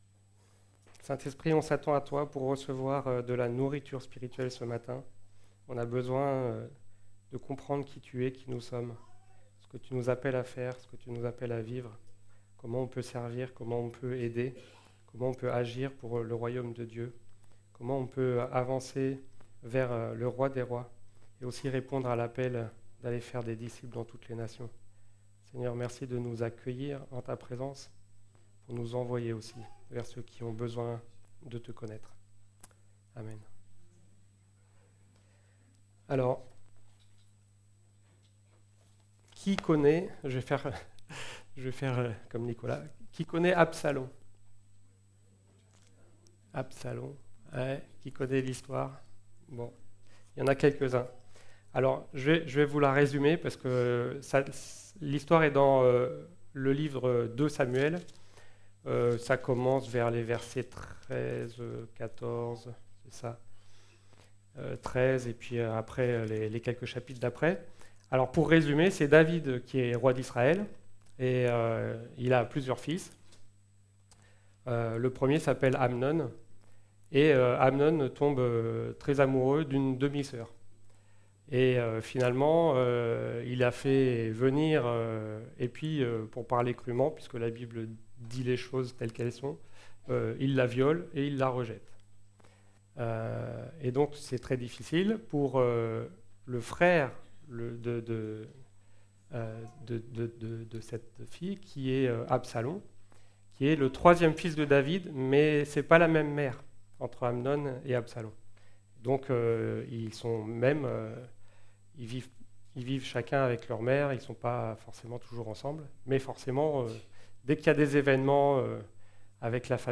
Type De Service: Culte